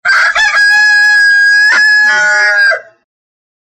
にわとりの鳴き声01